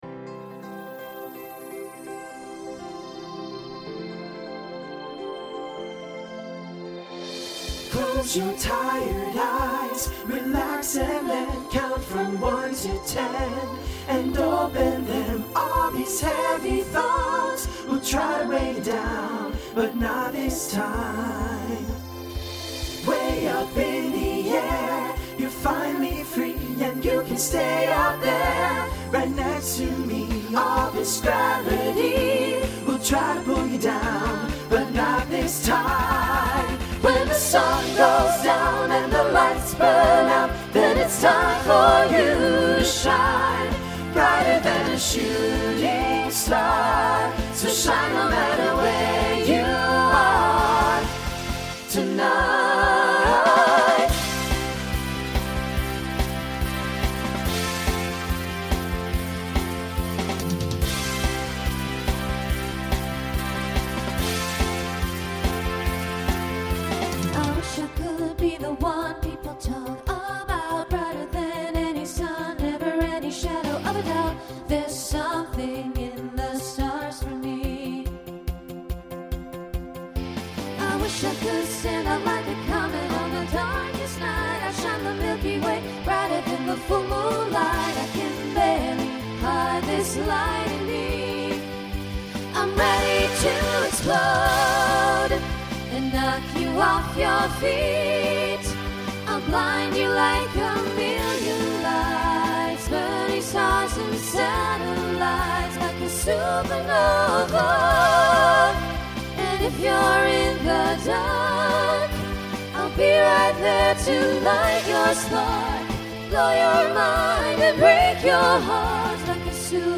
New ALL SATB voicing for 2025.